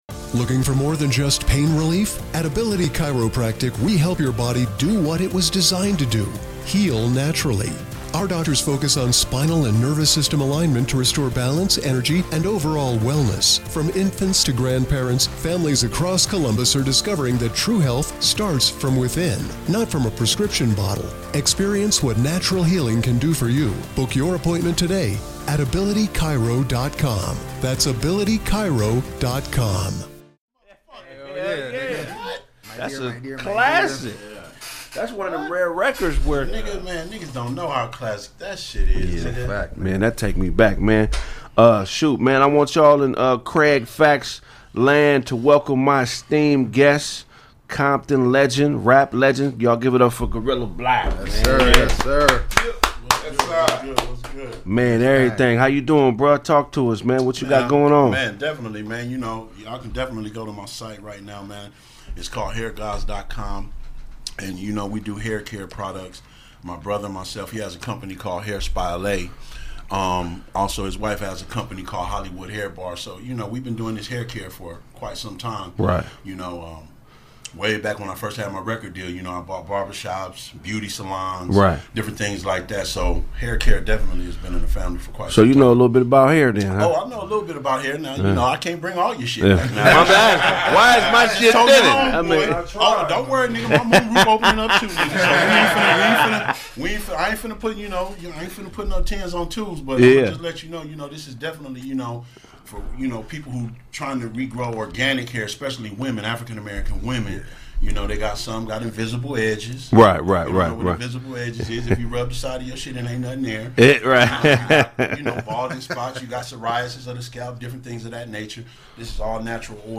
Guerilla Black in studio talking about being raised in Compton, the Music Industry, and the FEDs!